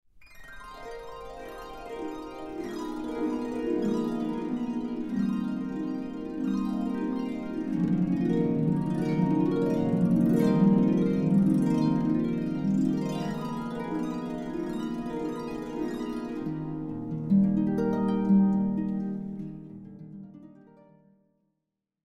A beautiful cd with 77 minutes harp solo music.